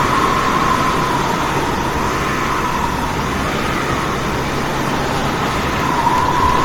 New rain and thunder effects added
storm.mp3